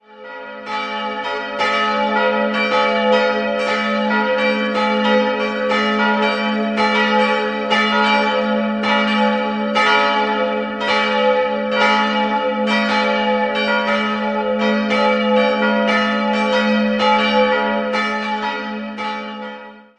Das Gotteshaus entstand im 15. Jahrhundert und wurde in der Barockzeit verändert. 3-stimmiges Geläute: a'-h'-d'' Die große und die kleine Glocken wurden 1955 von Friedrich Wilhelm Schilling in Heidelberg durchgesetzt.